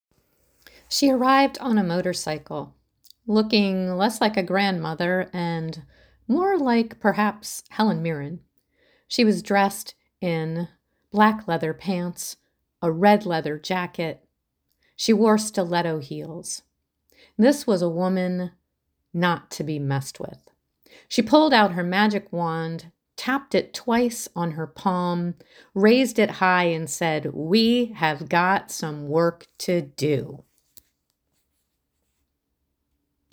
34 Years of Tuesday Night Storytelling
Debut of Original Story: Feminist Fairy Godmother. What might You wish for if you had a magic wand?